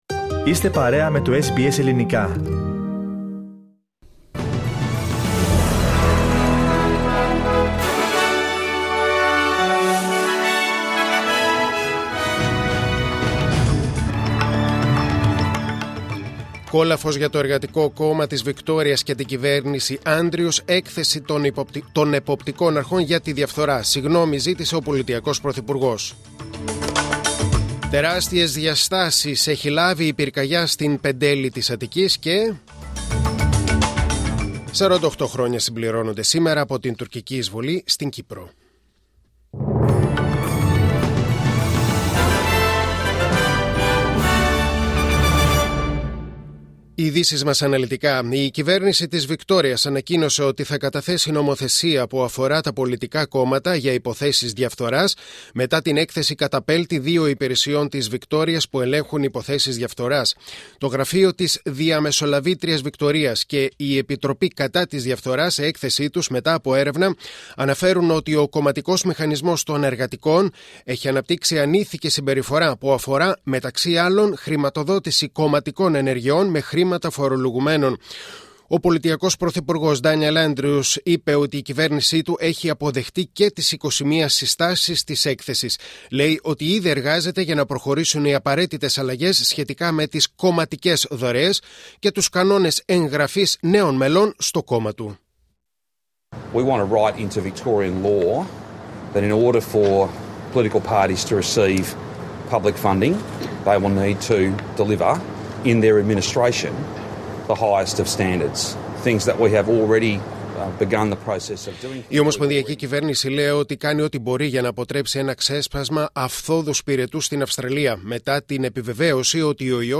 Δελτίο Ειδήσεων: Τετάρτη 20.7.2022